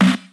• '00s Hip-Hop Steel Snare Drum Sound F# Key 162.wav
Royality free snare one shot tuned to the F# note. Loudest frequency: 855Hz